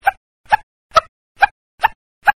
鳴り砂の音が聴けます。
1982年6月11日に採取された入野松原の砂の音！
「きれいな鳴り砂の音である。